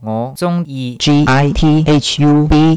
一个基于Java的粤语发音TTS,文字转语音.